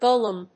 /ˈgolʌm(米国英語), ˈgəʊlʌm(英国英語)/